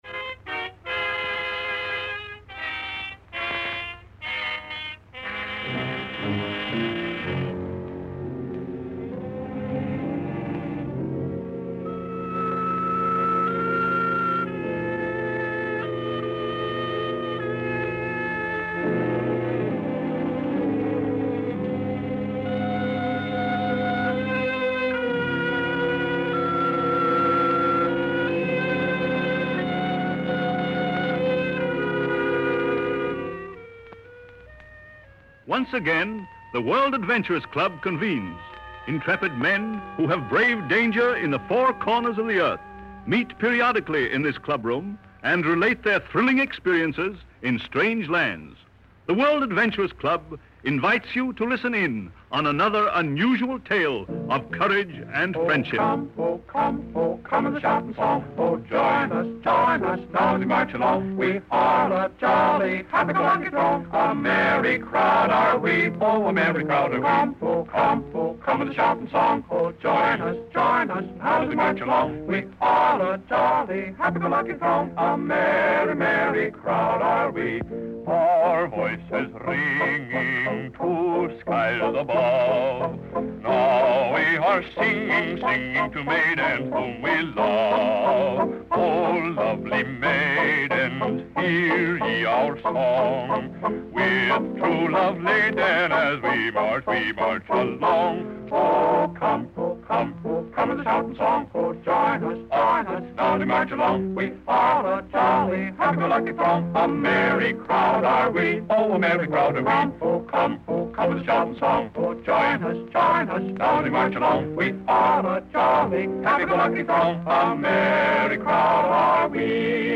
This gem from the golden age of radio takes us back to 1932, a time when the world was vastly different, yet the spirit of adventure was as alive as it is today.